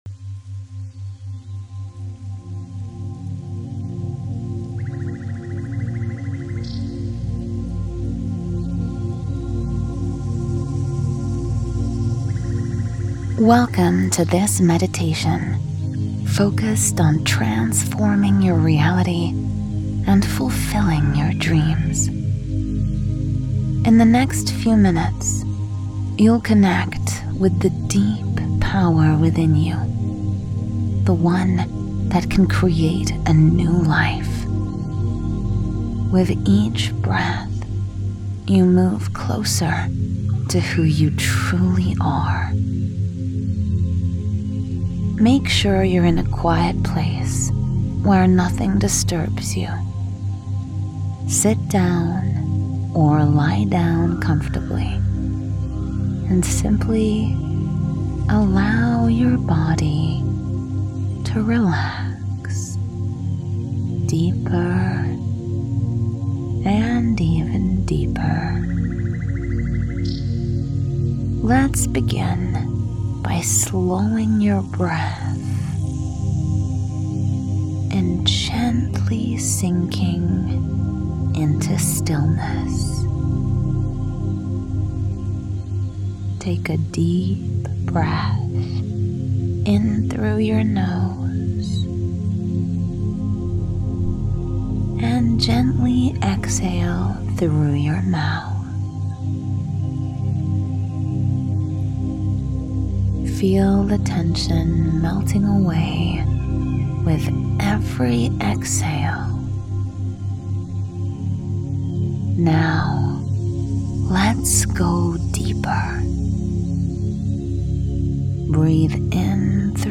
cosmicstudio8-guided-meditation